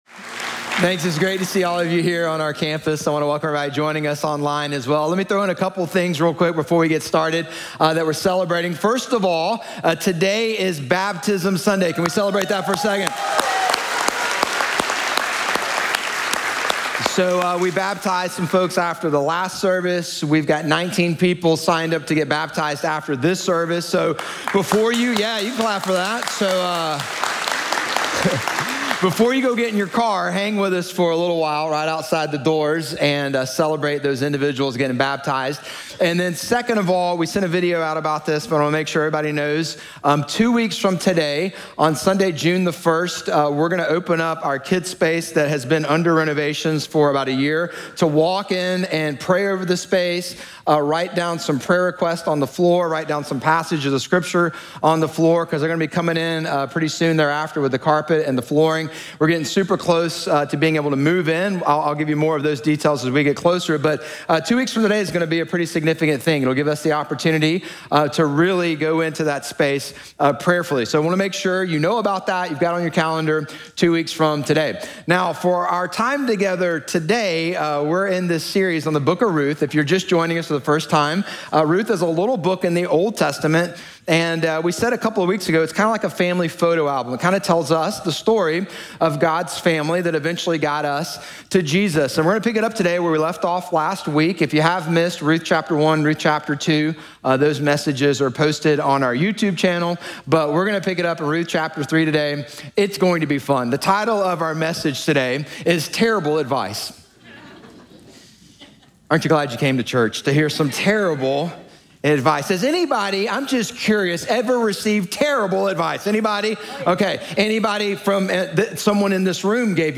Sermon Series Podcasts
Messages from NewHope Church in Durham, NC.